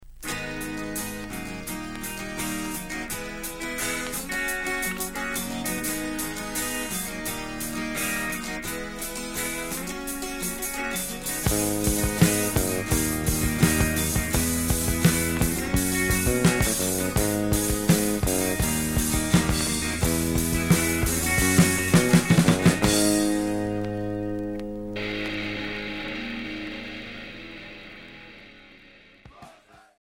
Punk oi